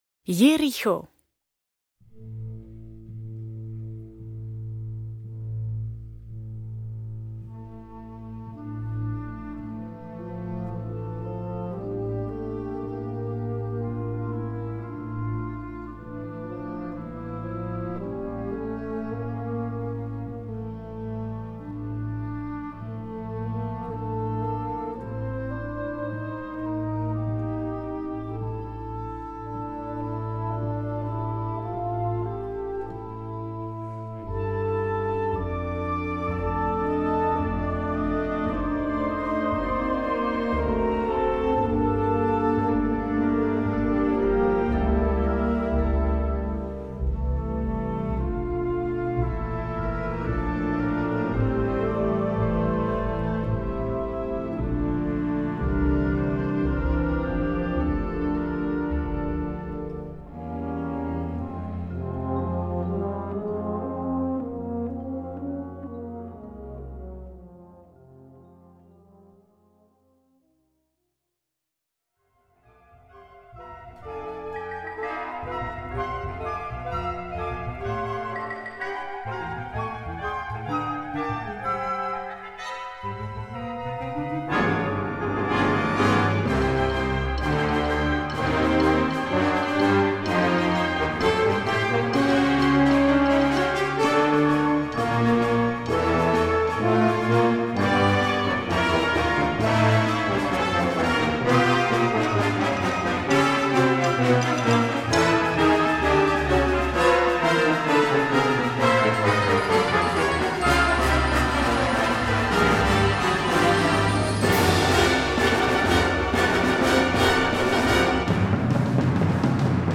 Besetzung: Blasorchester
Schwungvolle, nicht allzu schwierige Schnellpolka.